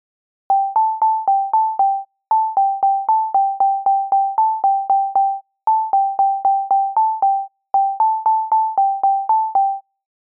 Rhinoで、sine波の一つだけのOscillatorでPitchを+12にし、Filterなし、Effectなしの状態をベースとして、
各巻ごとにOscillatorのlevel envelopeのみを変えています。
先頭に0.5秒の無音部を入れています。
巻ニの音色